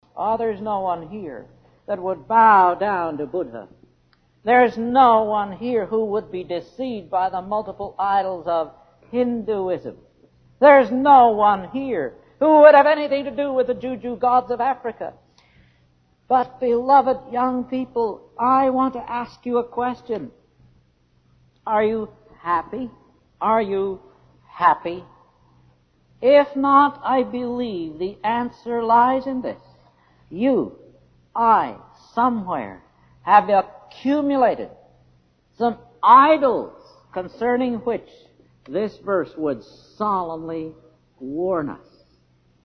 They go from the 1960s to the 80s, are of varying degrees of sound quality, but are pretty much all giving exactly the same message, despite there being about sixty of them.